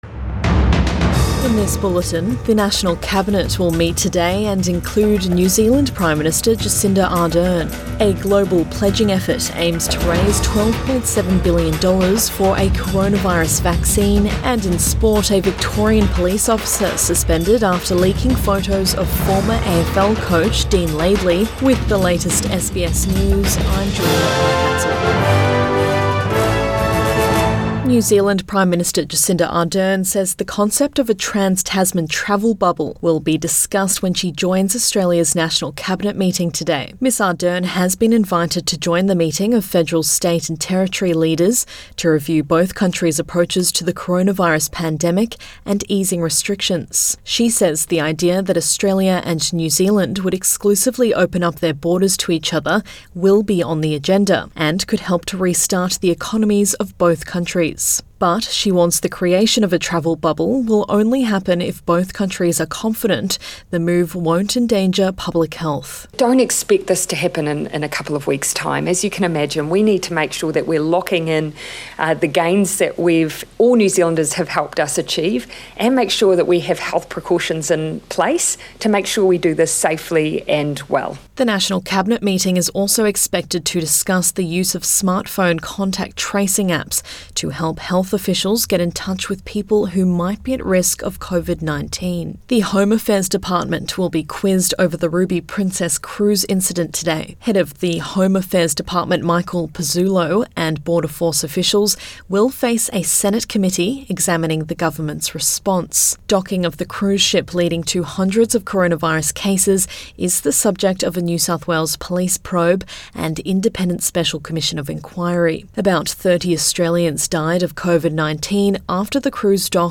AM bulletin 5 May 2020